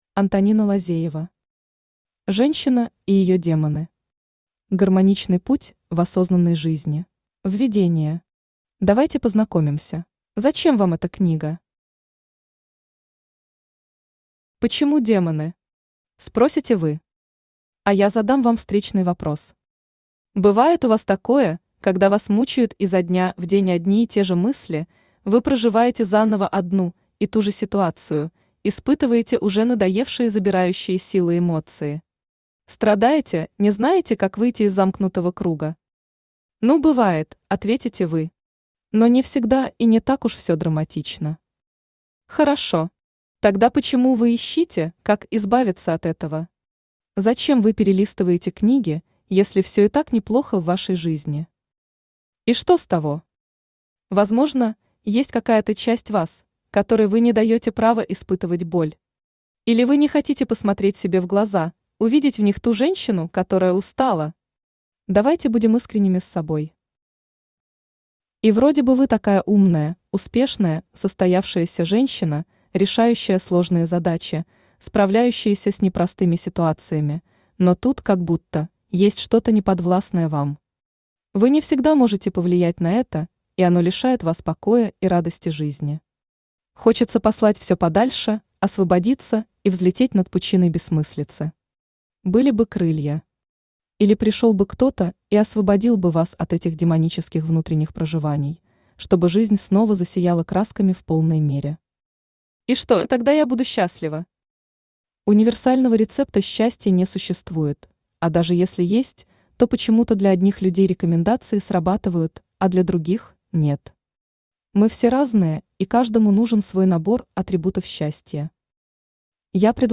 Гармоничный путь в осознанной жизни Автор Антонина Лазеева Читает аудиокнигу Авточтец ЛитРес. Прослушать и бесплатно скачать фрагмент аудиокниги